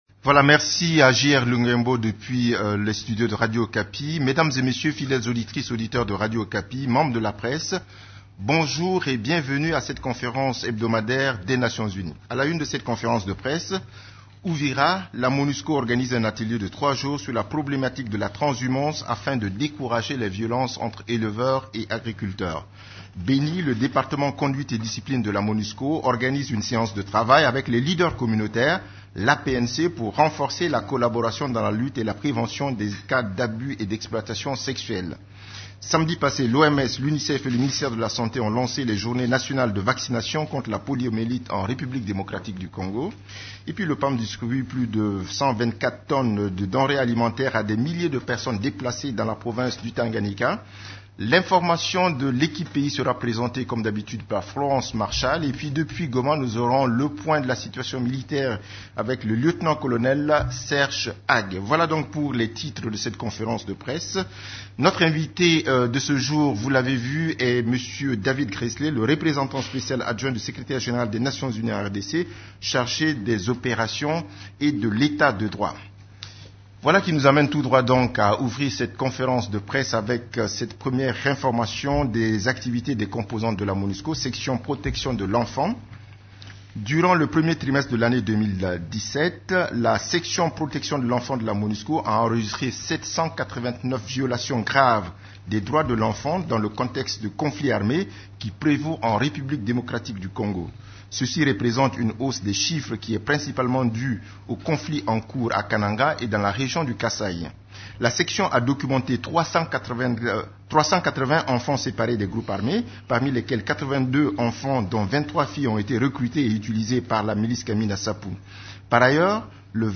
Conférence de presse du 12 avril 2017
La conférence de presse hebdomadaire des Nations unies du mercredi 12 avril à Kinshasa a porté sur la situation sur les activités des composantes de la MONUSCO, des activités de l’Equipe-pays ainsi que de la situation militaire à travers la RDC.